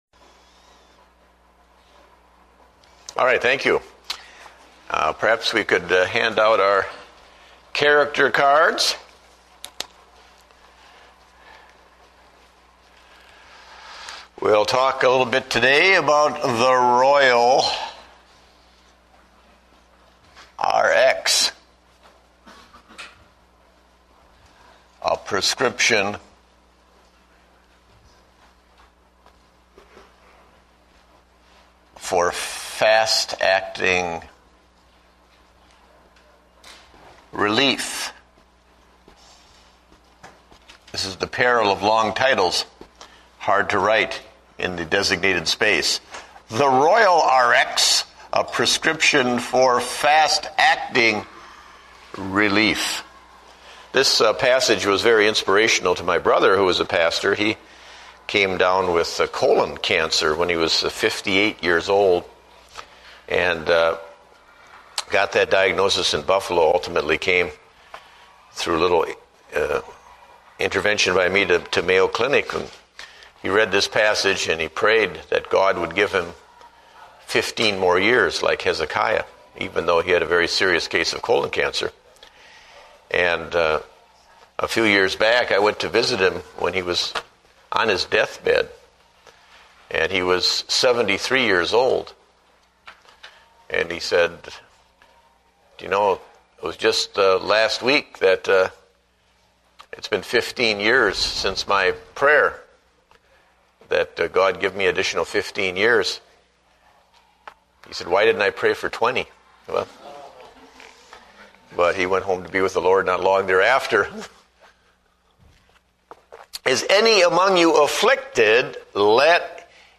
Date: October 19, 2008 (Adult Sunday School)